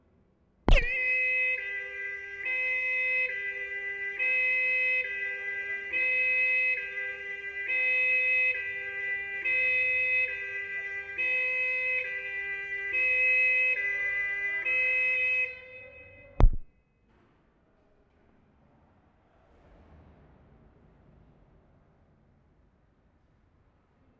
Amsterdam, Holland April 24/75
POLICE SIREN " " "
5. Taped from outside of building, vehicle inside. Less powerful siren. Interval m3, speakers on top.